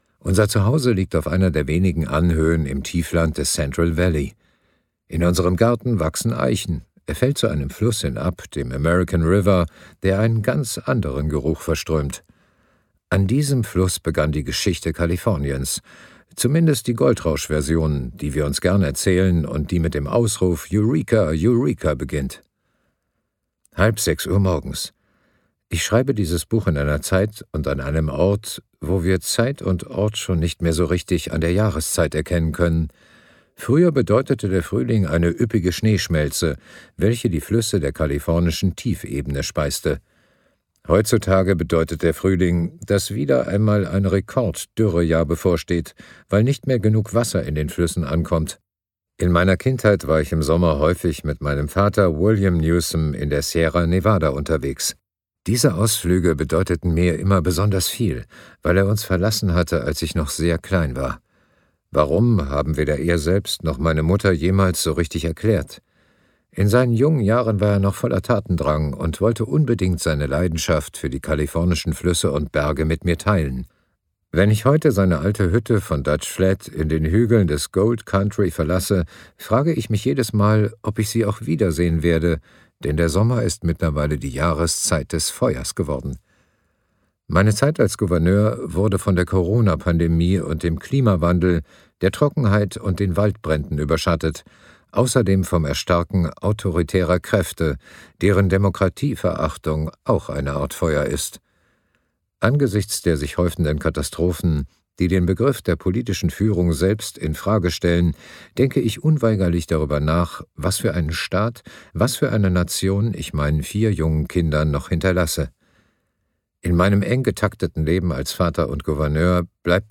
Das Hörbuch bietet einen Einblick in Gavin Newsoms politische Motivationen, seine Überzeugungen und Visionen.
Gekürzt Autorisierte, d.h. von Autor:innen und / oder Verlagen freigegebene, bearbeitete Fassung.